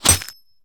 bullet_impact_glass_04.wav